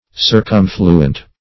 Circumfluent \Cir*cum"flu*ent\